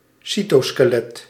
Ääntäminen
IPA: /ˈsitoskəˌlɛt/